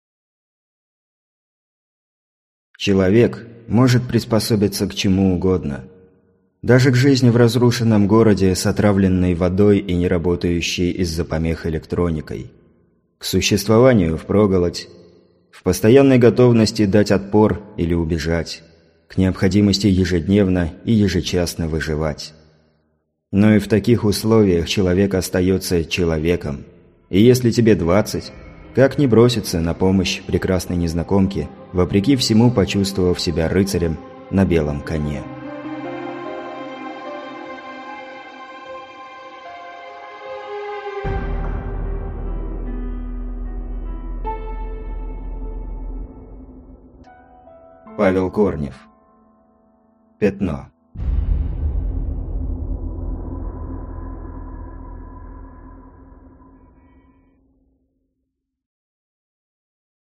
Аудиокнига Пятно | Библиотека аудиокниг